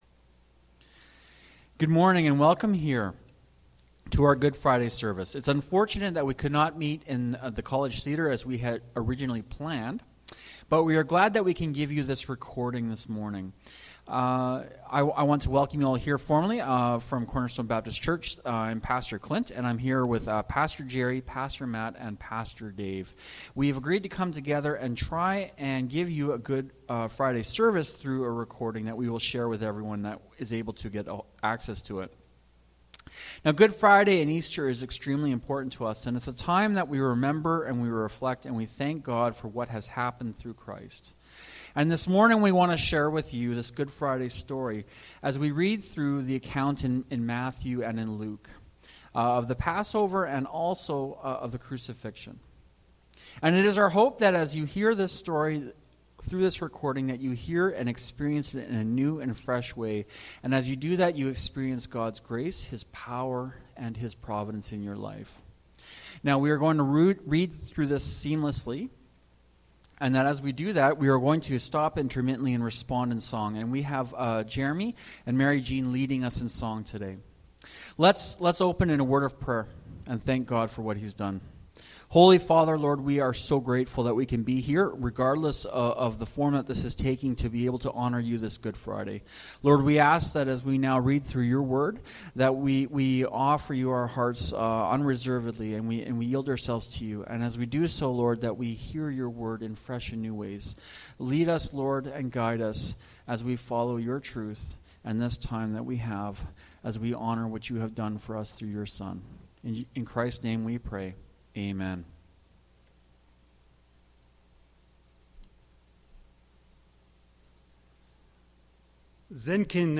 Good Friday Service
Good-Friday-Service-2020-1.mp3